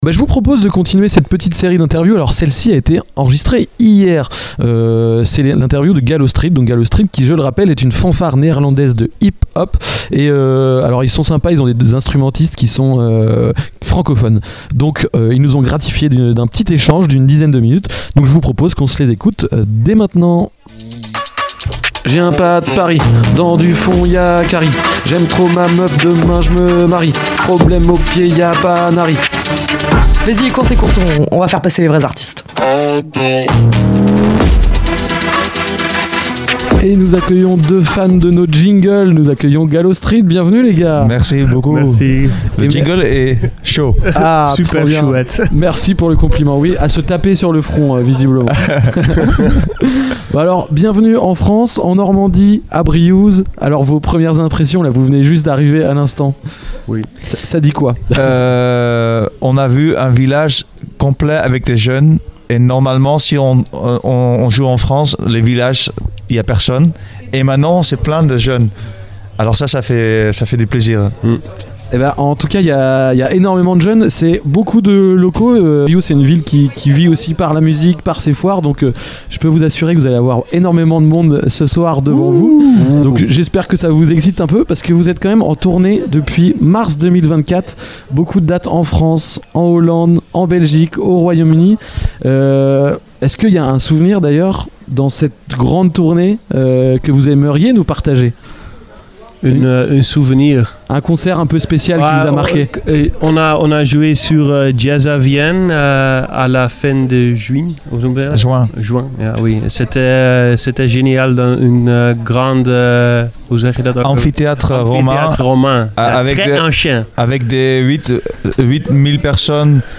Dans cette interview réalisée dans le cadre de l’émission spéciale enregistrée au festival Art Sonic à Briouze, les radios de l’Amusicale — Ouest Track, Station B, PULSE, Kollectiv’, 666, Radio Sud Manche, Radio Coup de Foudre, Radar, Phénix, Radio Campus Rouen et TST Radio — sont parties à la rencontre des artistes qui font vibrer le festival.